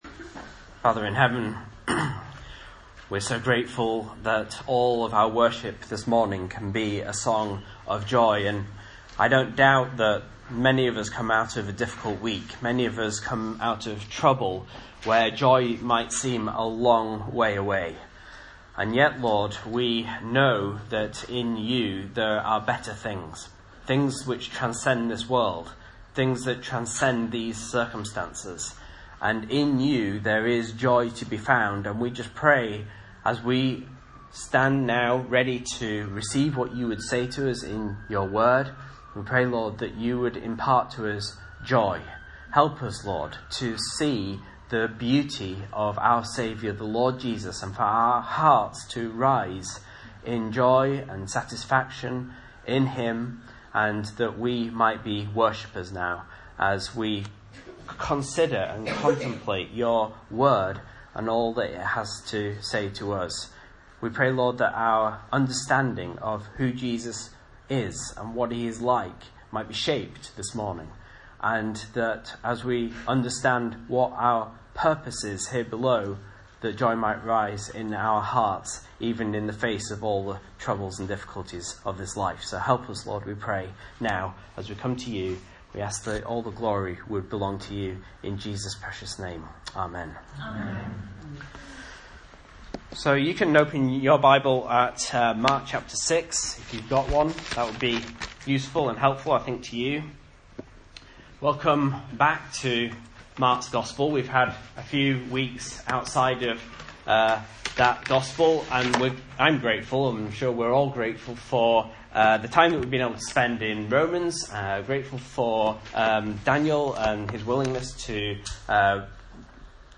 Message Scripture: Mark 6:1-29 | Listen